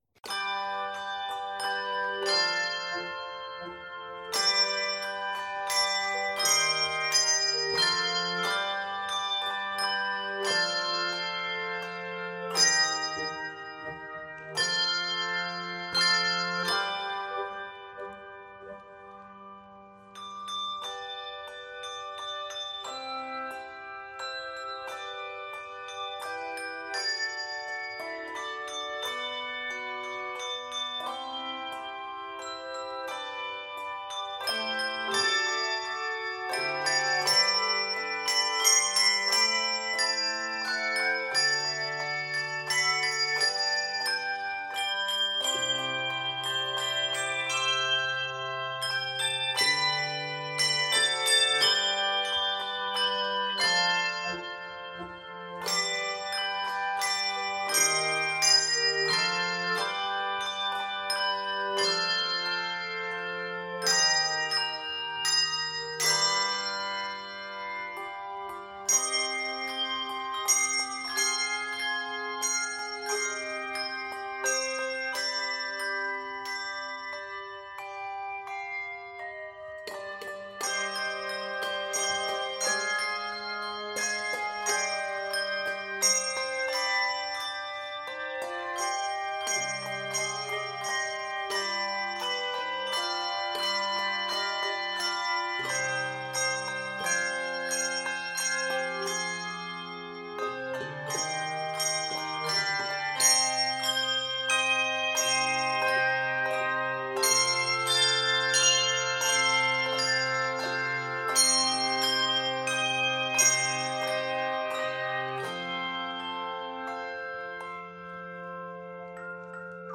Keys of G Major and c minor.